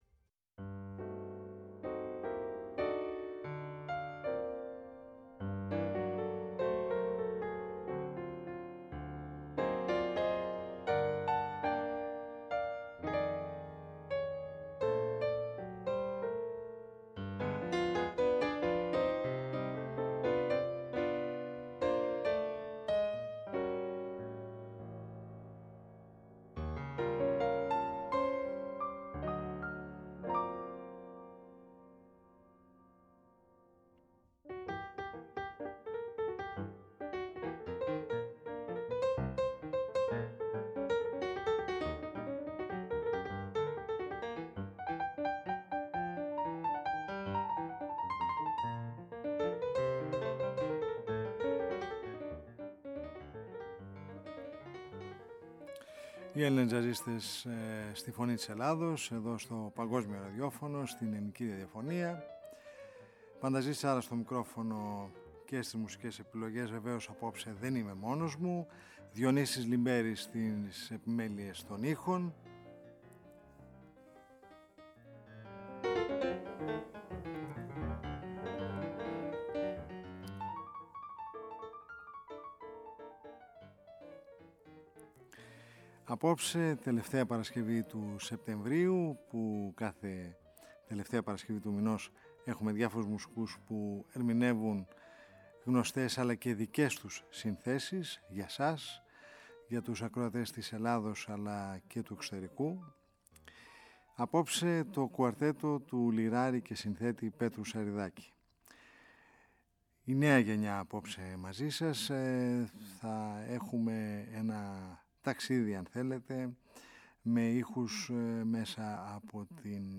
κουαρτέτο
λύρα – τραγούδι
λαούτο – τραγούδι
ηλεκτρικό μπάσο